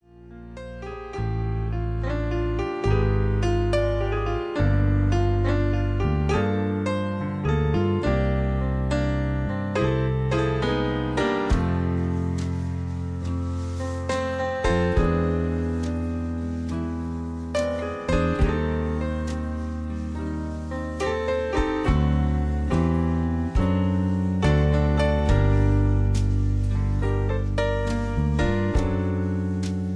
backing tracks
rock